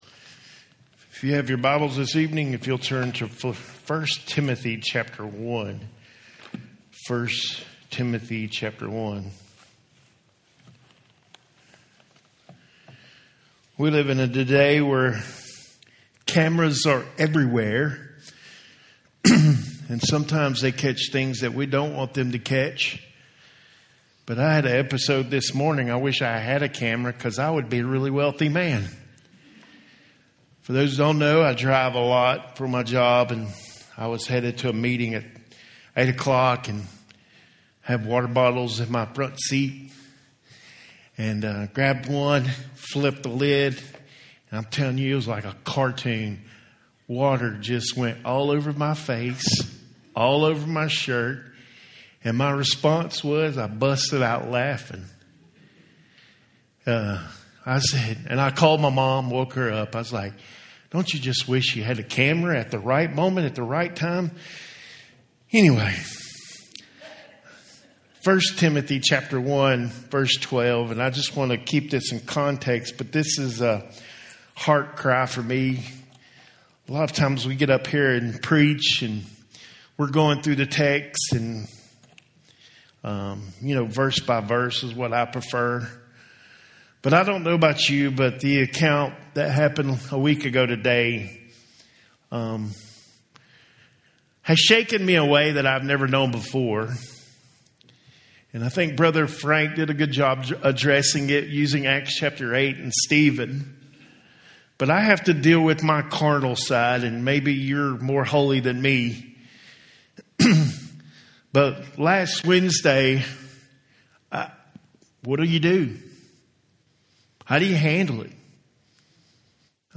Home › Sermons › The Good Fight